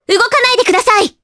Mirianne-Vox_Skill5_jp.wav